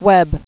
click to hear the word) (tv) (te' ve') n. Television.